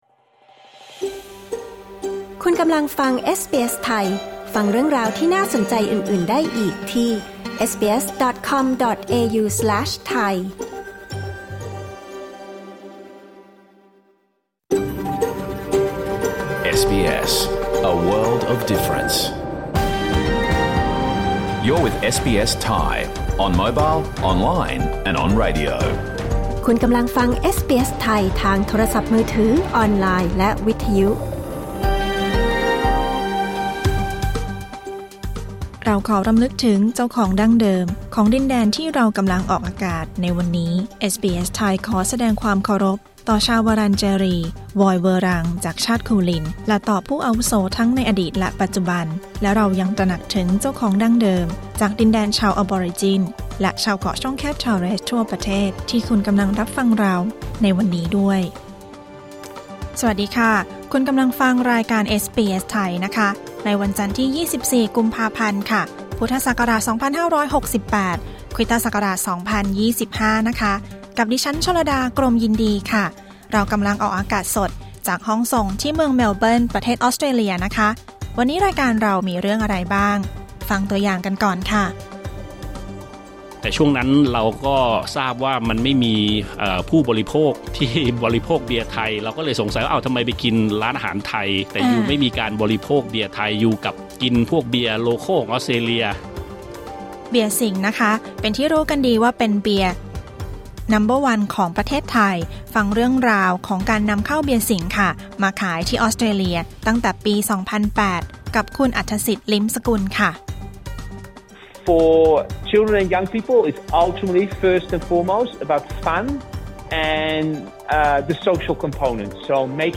รายการสด 24 กุมภาพันธ์ 2568